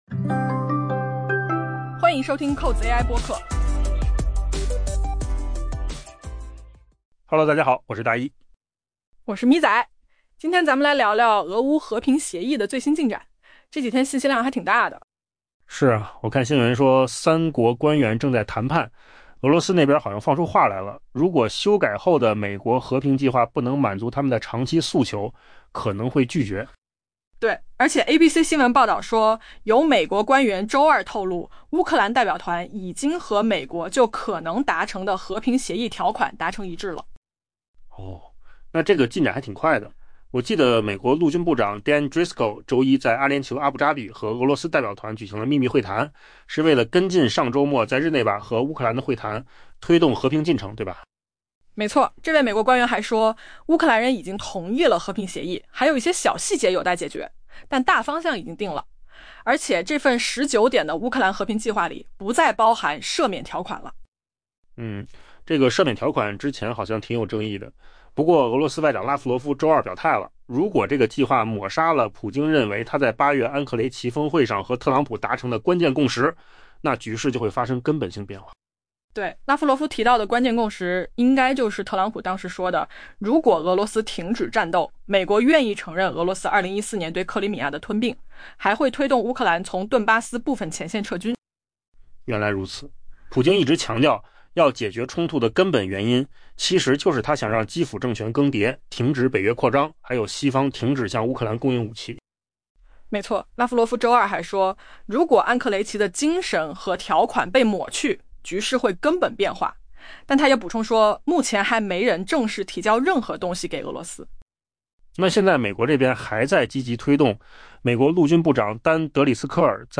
AI播客：换个方式听新闻 下载mp3
音频由扣子空间生成音频由扣子空间生成